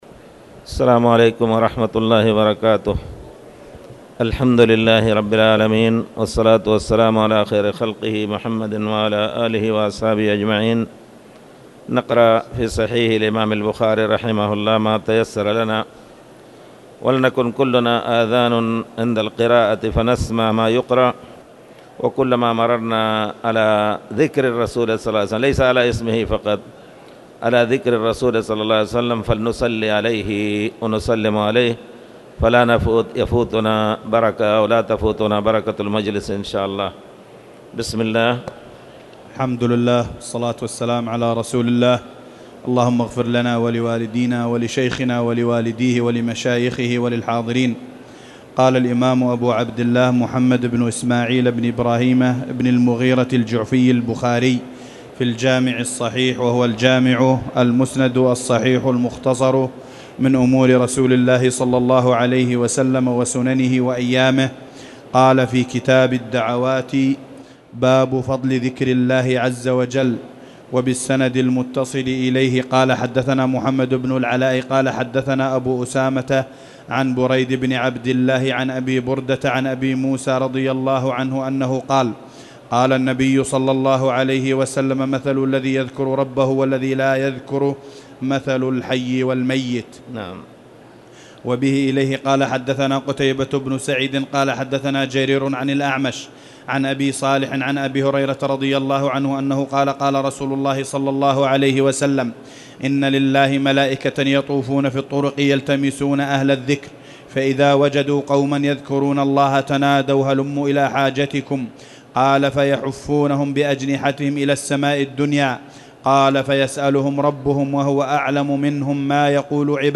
تاريخ النشر ٢٣ ربيع الثاني ١٤٣٨ هـ المكان: المسجد الحرام الشيخ